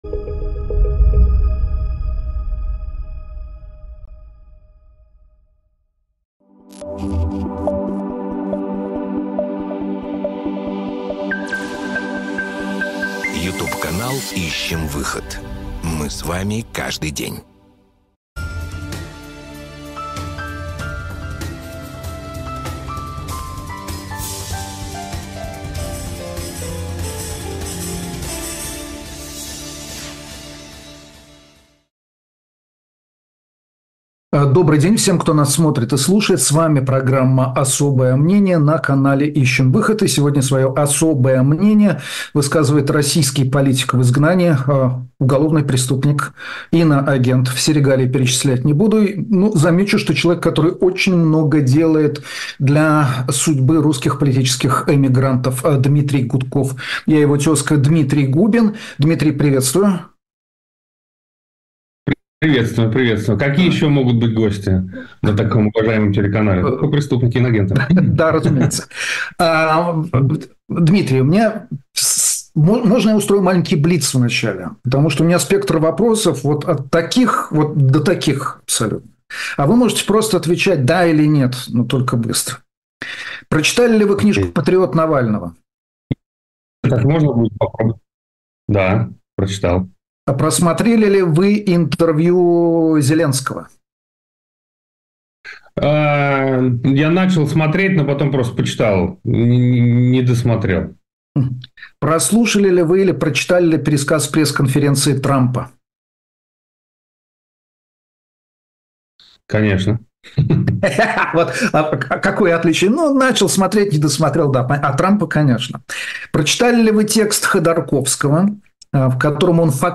Эфир ведёт Дмитрий Губин